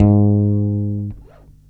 22-G#2.wav